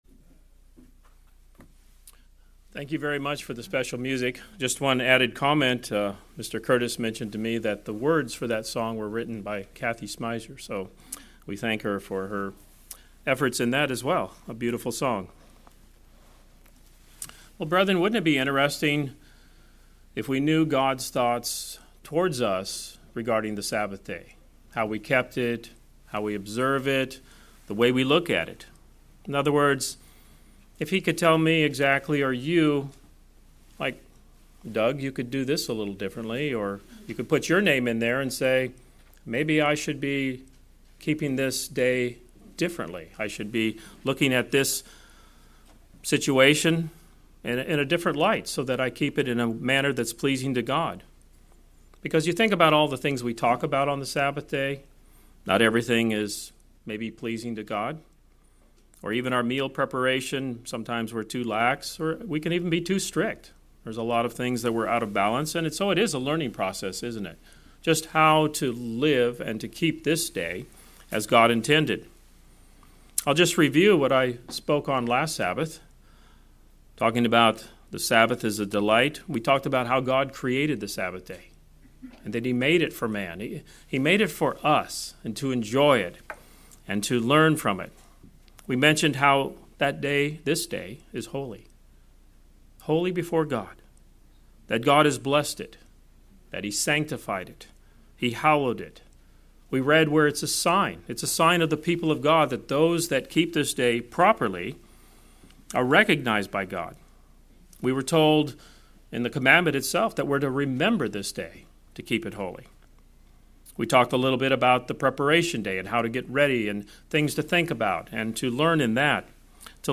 The weekly Sabbath plays a significant role in the lives of Christians. This sermon covers three additional points which are helpful in fulfilling the command to delight in the Sabbath.